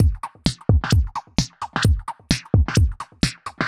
Index of /musicradar/uk-garage-samples/130bpm Lines n Loops/Beats